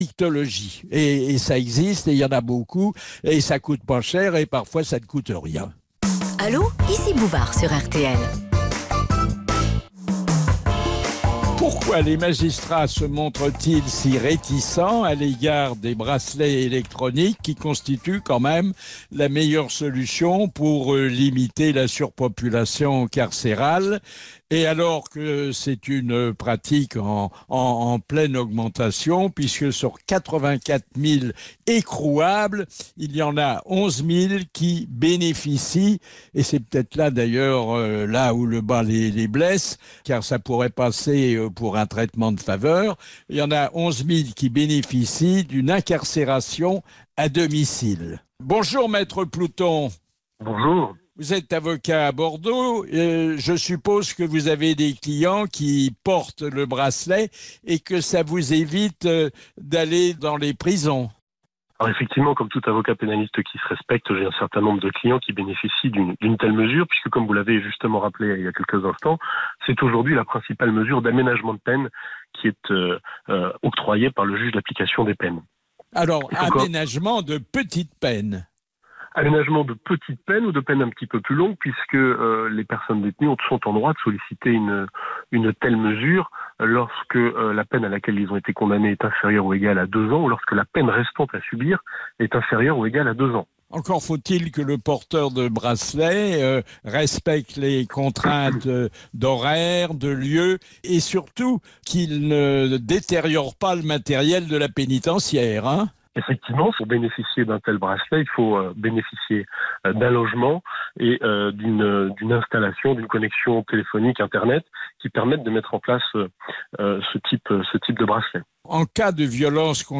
Interview
sur RTL dans l’émission « Allo, Ici Bouvard »